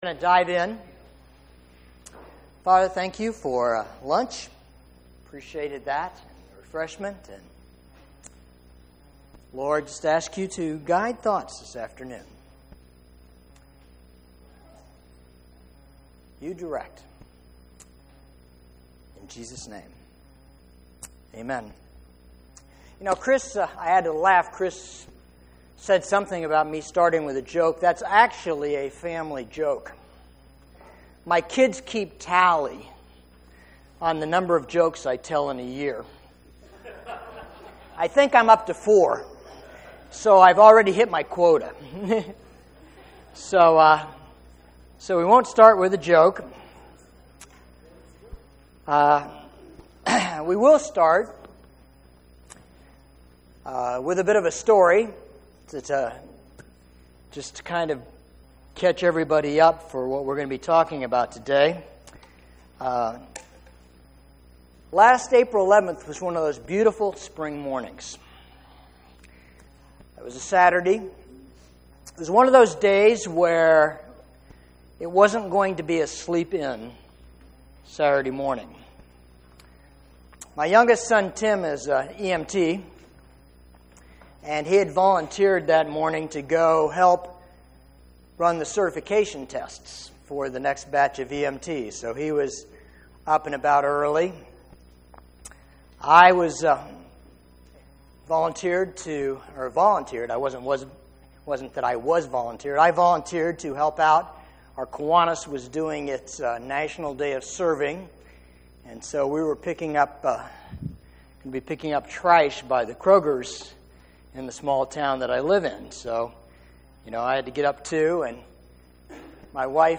GCLI SERMON 0094.mp3